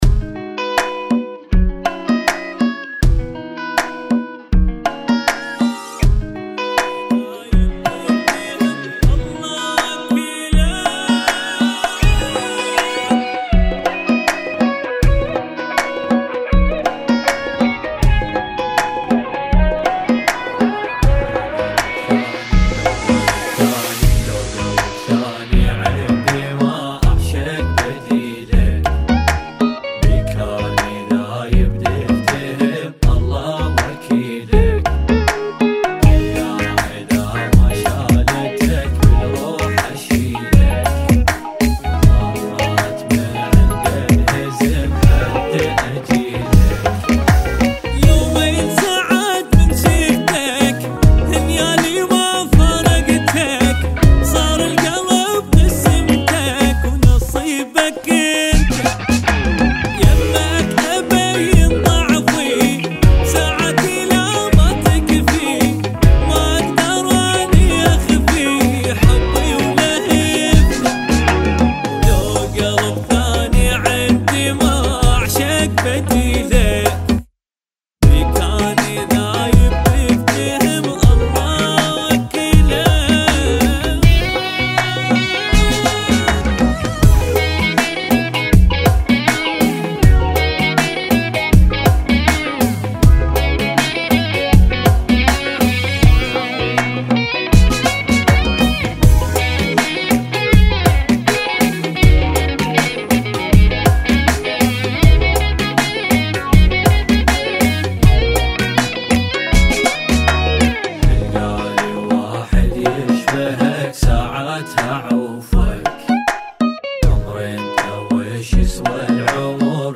[ 80 Bpm ]